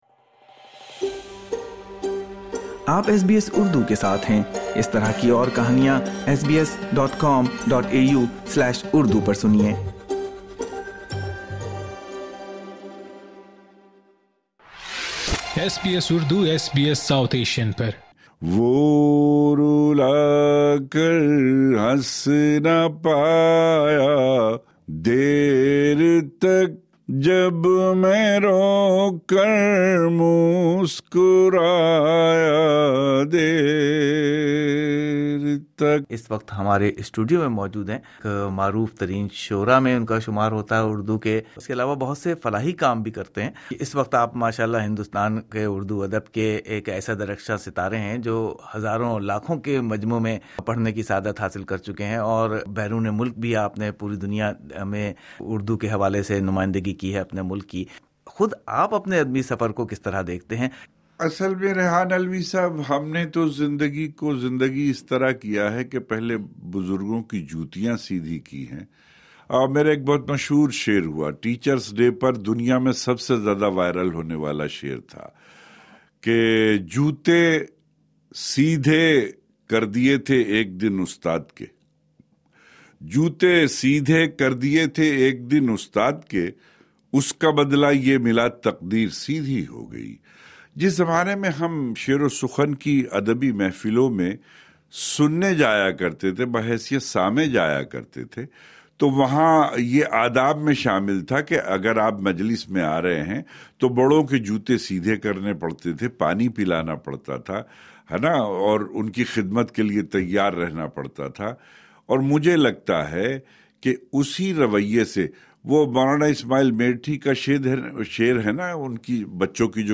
Visiting poet Nawaz Deobandi in the SBS studio (Credit SBS Urdu).